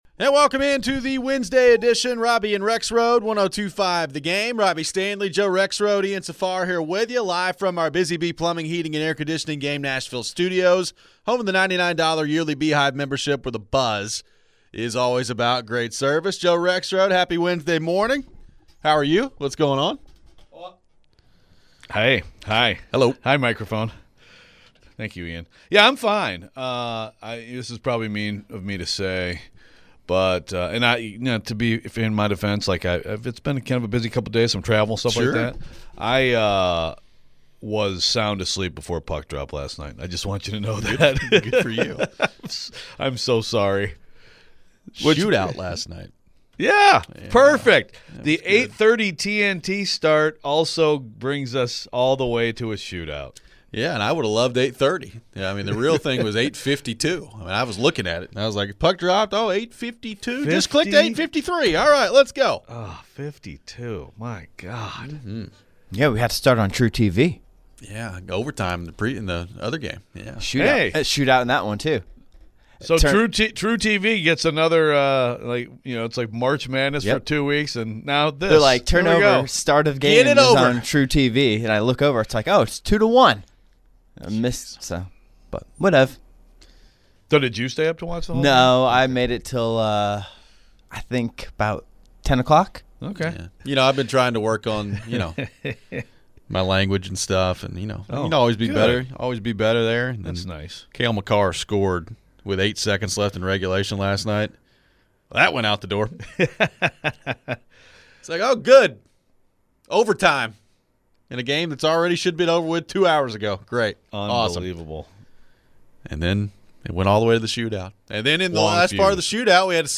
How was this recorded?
We take your phones. Will the Steelers move on from Mike Tomlin?